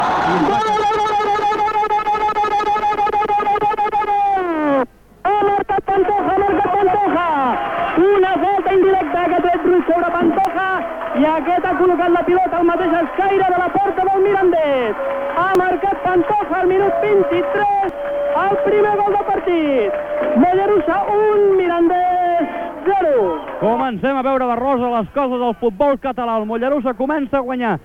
Transmissió del partit de futbol masculí entre el Mollerussa i el Mirandès.
Esportiu